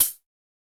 Perc (8).wav